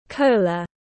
Nước ngọt có ga tiếng anh gọi là cola, phiên âm tiếng anh đọc là /ˈkəʊ.lə/
Cola /ˈkəʊ.lə/